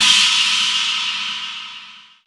• Roomy Crash Sound F Key 04.wav
Royality free crash cymbal single hit tuned to the F note. Loudest frequency: 3743Hz
roomy-crash-sound-f-key-04-j4e.wav